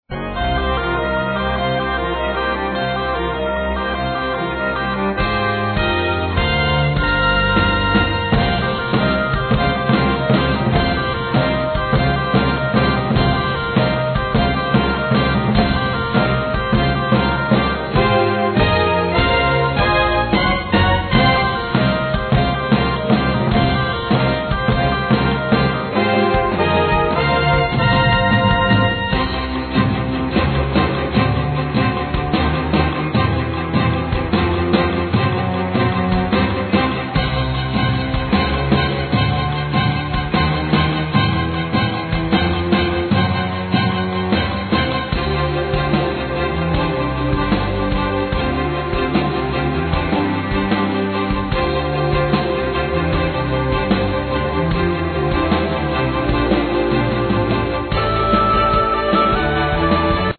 Keyboards, Vocals
Drums, Percussions, Bass guitar, Vocals
Flute
Cello
Trombone
Oboe
Violin
Trumpet
Guitar